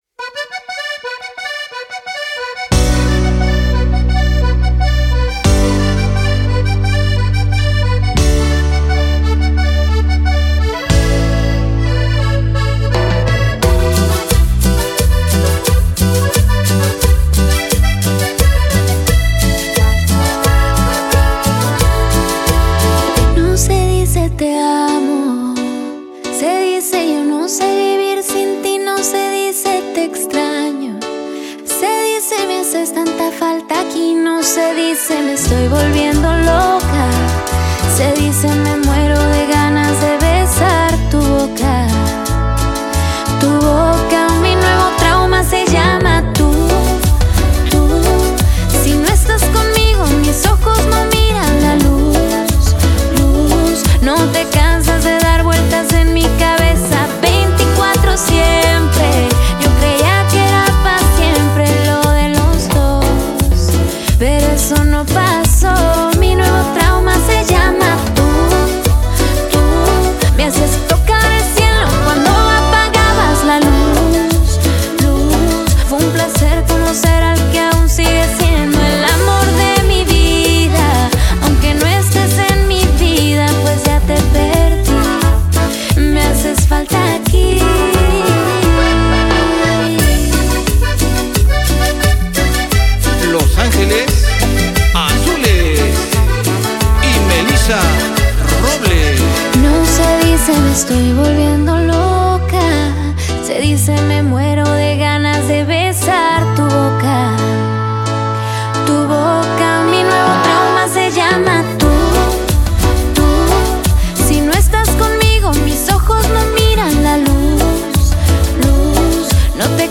Pop íntimo